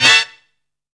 BLAST TRUMPS.wav